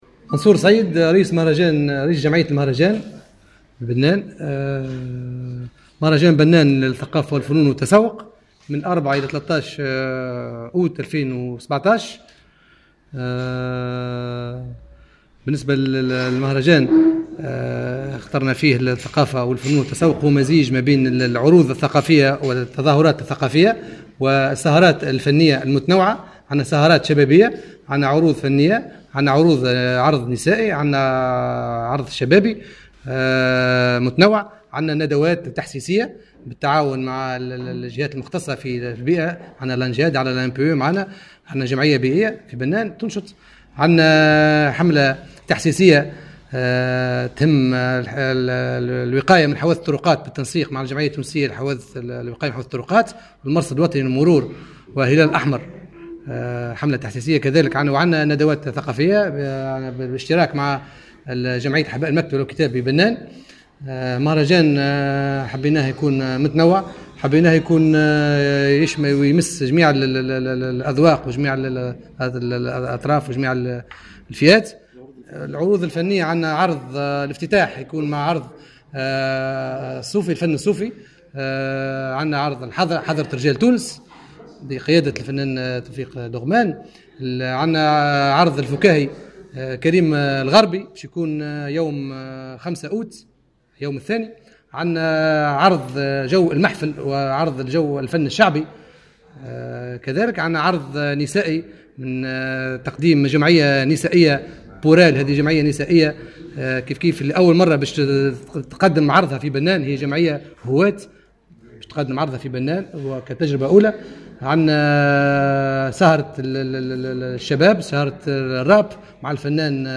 في تصريح لمراسلنا